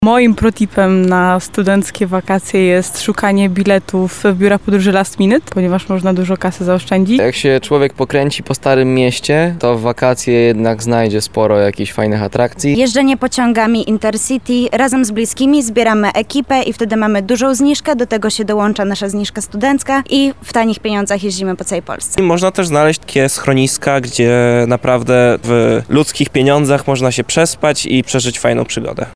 Lubelscy studenci dzielą się wakacyjnymi poradami.
sonda uliczna